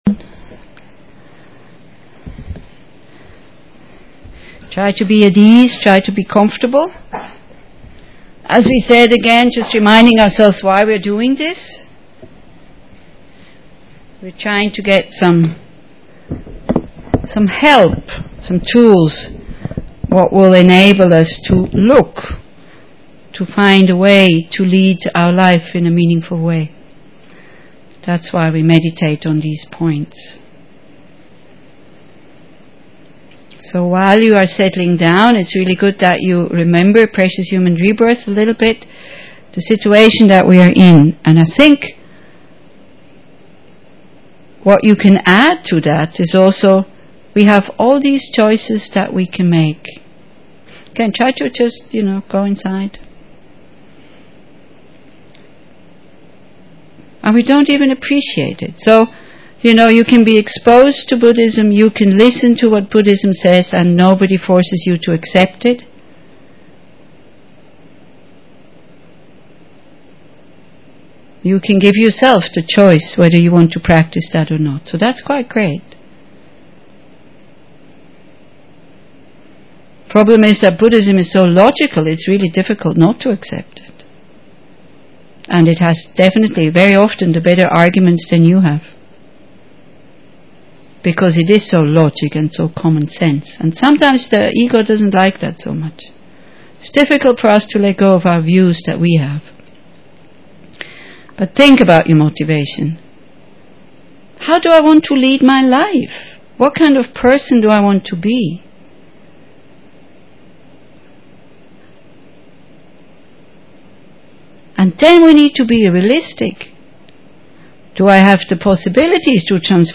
phr and impermanence meditation 2.mp3